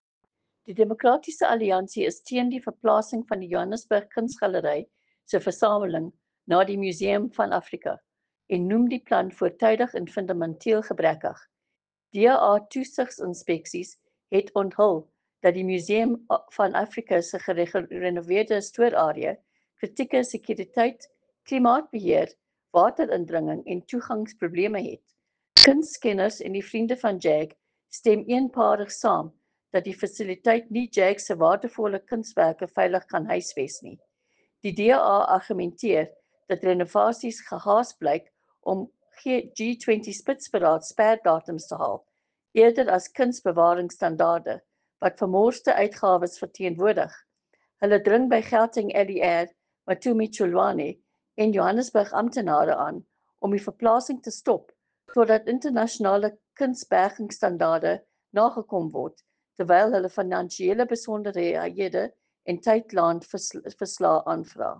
Afrikaans soundbites by Leanne De Jager MPL.